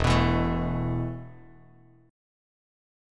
Esus4 chord